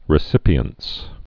(rĭ-sĭpē-əns) also re·cip·i·en·cy (-ən-sē)